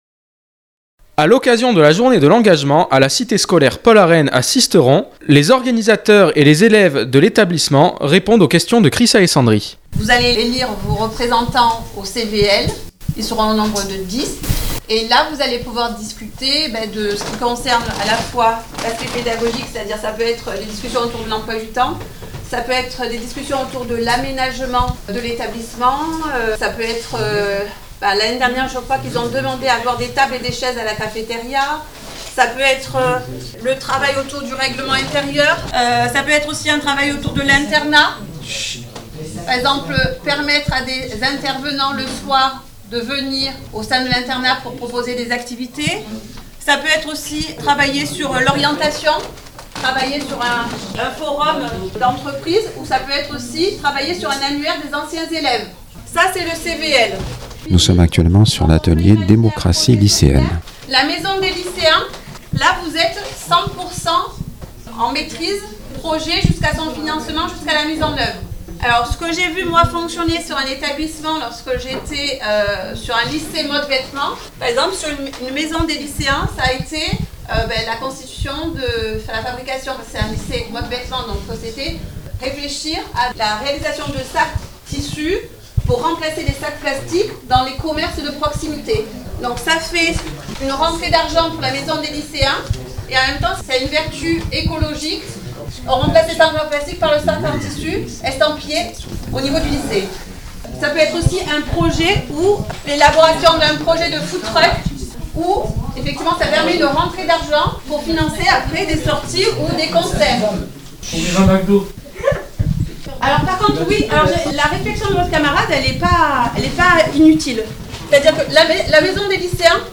les réactions de divers organisateurs et acteurs de cette journée pédagogique.